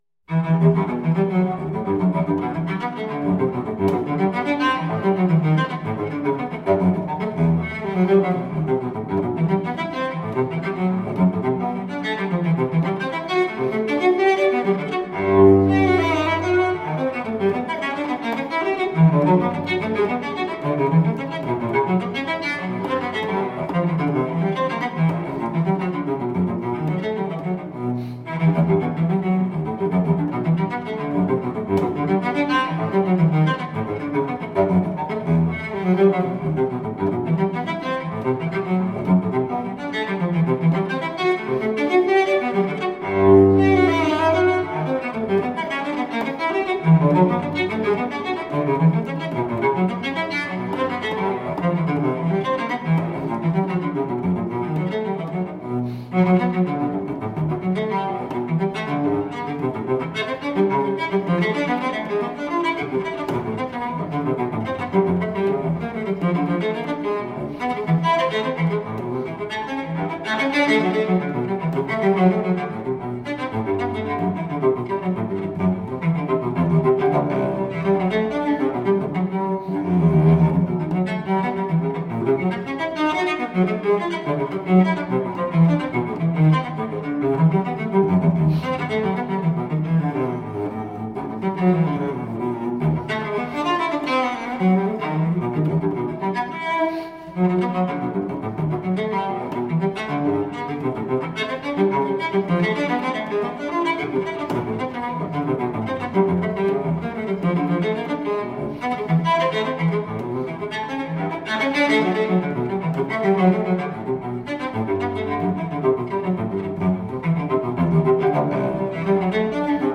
Classical, Baroque, Instrumental, Cello